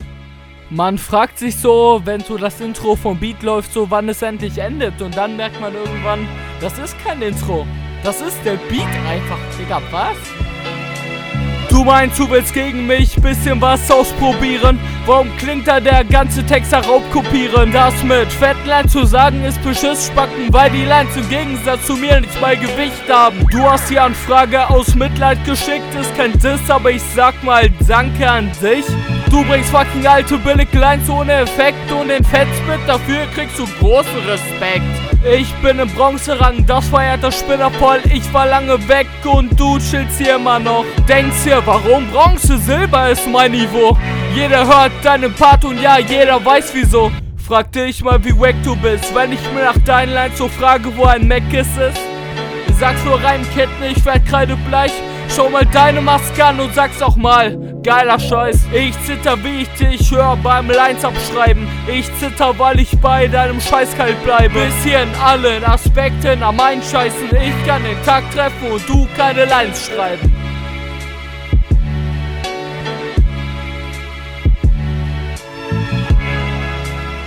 Stimme scheint viel zu laut zu sein im gegensatz zum Beat, ausserdem fehlt glaube auch …
Flow cooler und die Lines lustig.
Der Stimmeinsatz ist etwas kräftiger als beim Gegner aber der Flow ist auf einem recht …